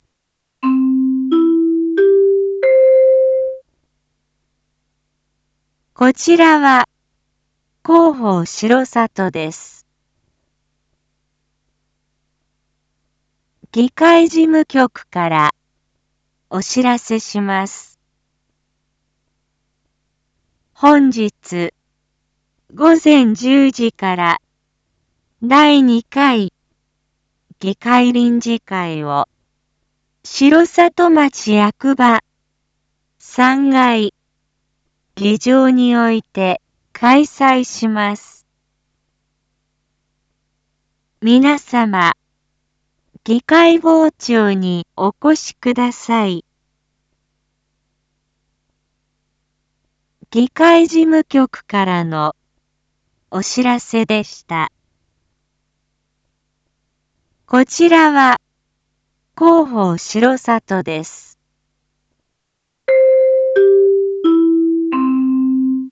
一般放送情報
Back Home 一般放送情報 音声放送 再生 一般放送情報 登録日時：2024-08-22 07:01:07 タイトル：②第２回議会臨時会の開催について インフォメーション：こちらは広報しろさとです。